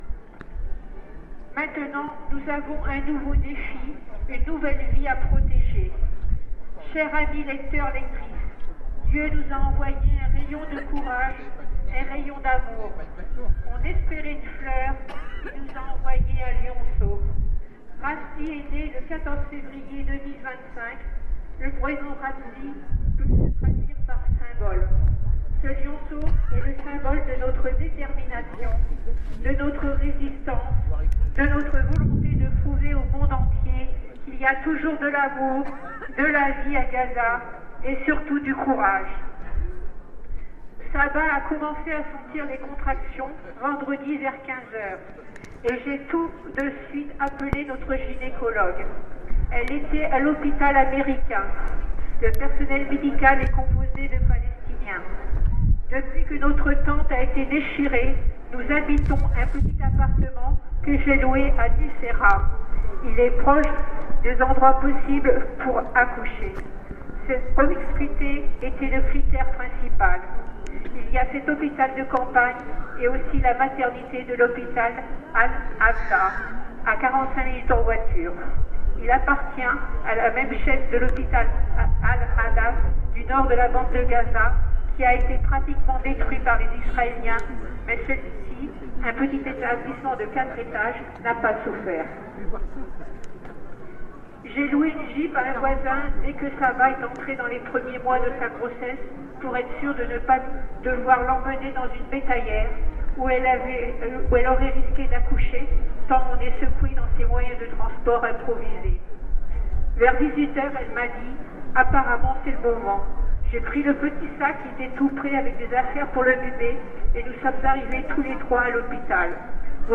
Enregistrements des prises de paroles et des slogans de la manifestation.
Lecture d’une lettre de Palestine, récit d’un accouchement
Place du Bareuzai